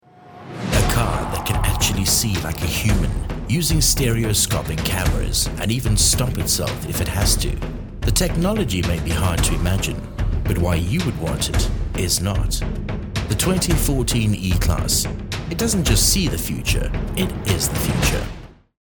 My voice is clear, engaging, versatile, and perfect for:
Fast turnaround. Studio-quality sound. No fuss, just clean, compelling audio tailored to your vision.
Merc-Ad-1.mp3